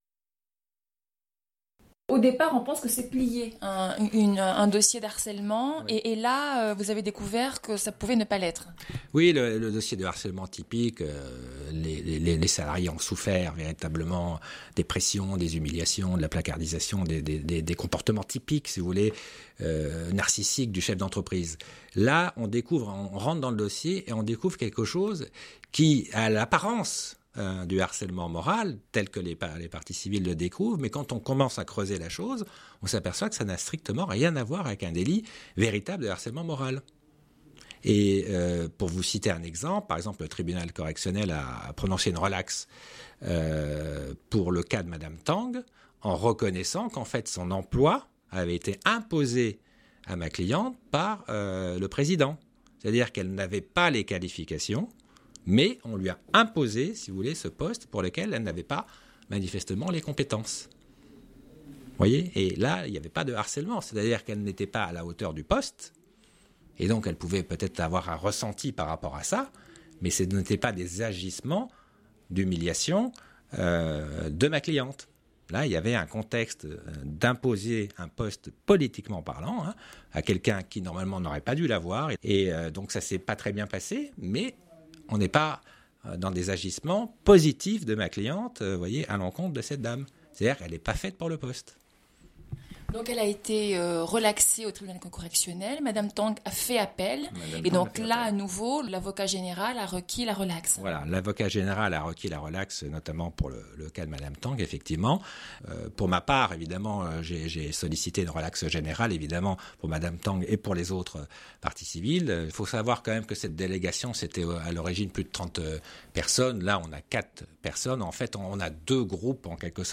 ITW